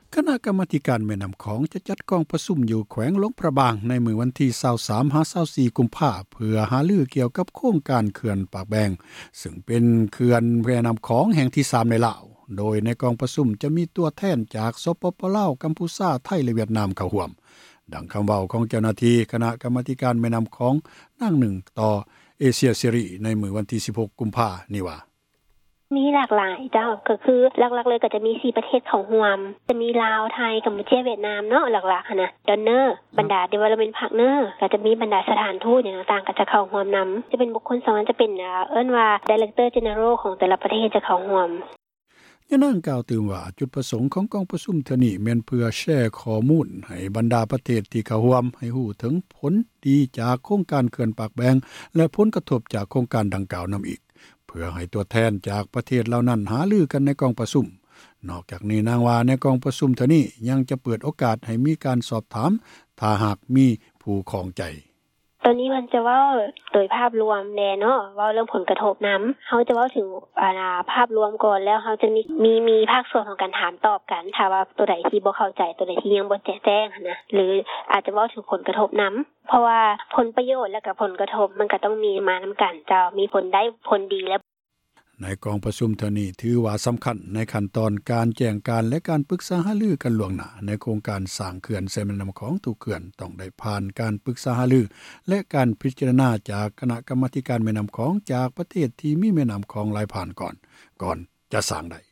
ດັ່ງຄຳເວົ້າ ຂອງເຈົ້າຫນ້າທີ່ ຄນະ ກັມມາທິການ ແມ່ນ້ຳຂອງ ທ່ານນຶ່ງ ທີ່ກ່າວຕໍ່ ເອເຊັຽເສຣີ ໃນວັນທີ 16 ກຸມພາ ນີ້ວ່າ: